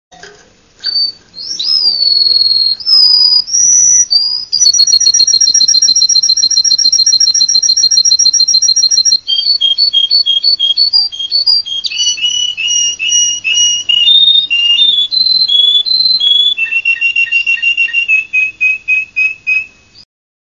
Пение русской канарейки овсяночного напева